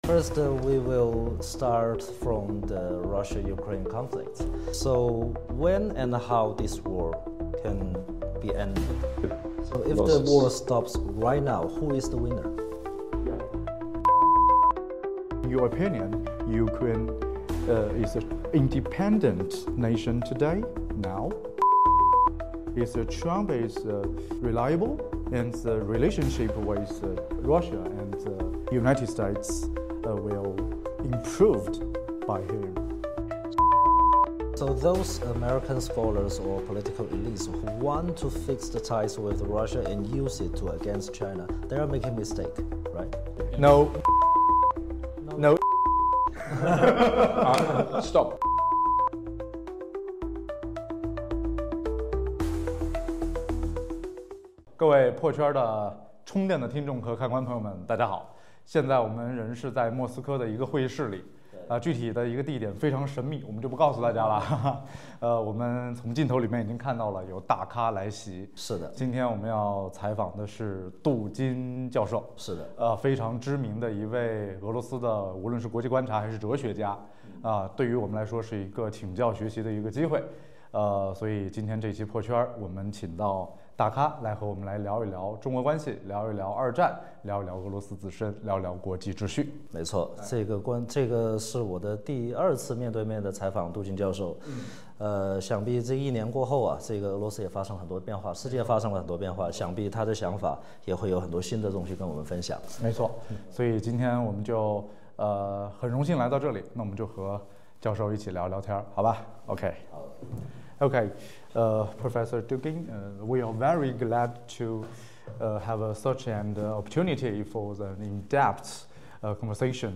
Exclusive interview with "Putin's brain" Dugin: No one wins in the Russia-Ukraine War